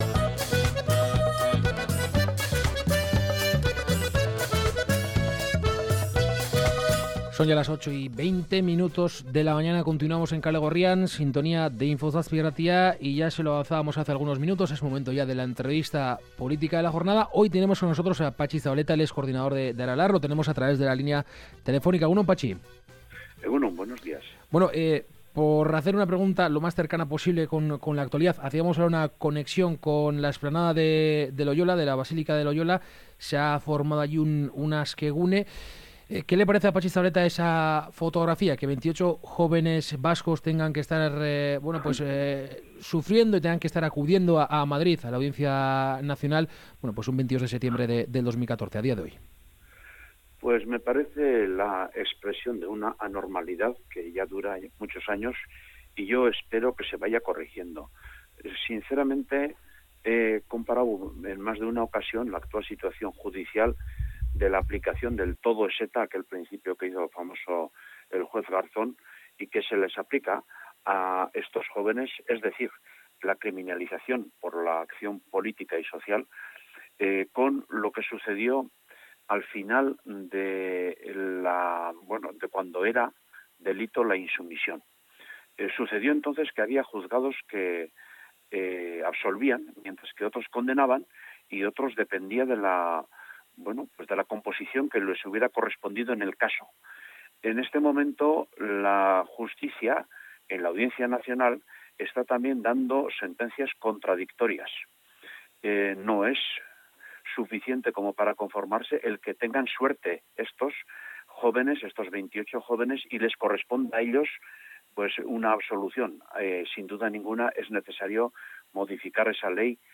Esta mañana en Kalegorrian hemos entrevistado a Patxi Zabaleta, coordinador general de Aralar. Zabaleta nos ha confirmado que el próximo 15 de noviembre, con la celebración del VI congreso de Aralar, dejará la dirección del partido.